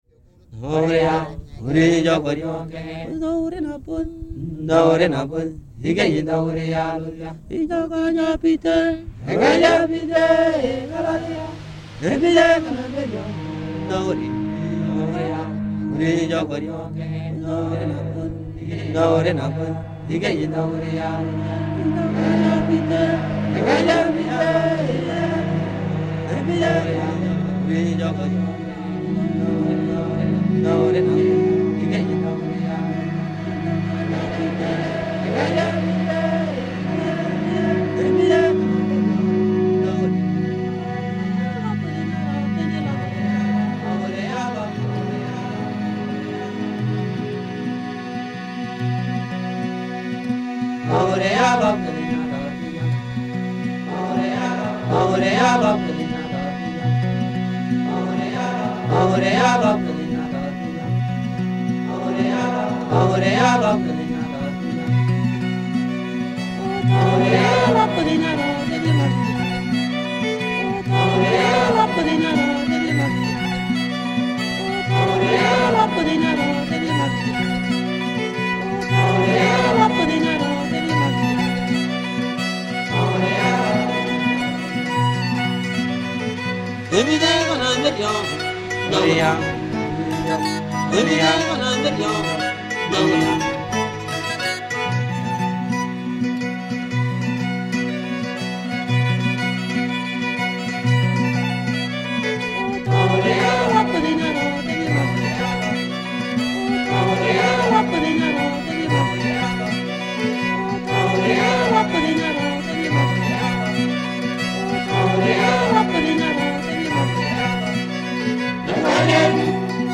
The melody, compound rhythm and tempo reflect this balance and resonate as song of celebration and joy, even one to dance to – a port-cruinn perhaps?
Port-cruinn (pr. porsht-crooeen) is Scottish Gaelic for "jig" and is intended to sit alongside the joyful Noriya. The main jig melody draws on the Noriya theme, flipping from minor to major, and is carried by an underlying drone.
There are two Scottish fiddles from two different generations.